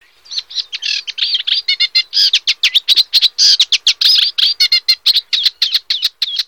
Rousserolle effarvatte
Acrocephalus scirpaceus
rousserolle_e.mp3